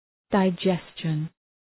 Προφορά
{dı’dʒestʃən}